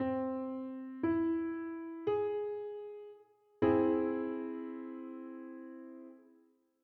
The augmented triad has a raised 5th when compared to major, which is what gives it a unique sound.
Figure 11.2 C augmented triad displayed melodically.
C-Augmented-Triad-S1.wav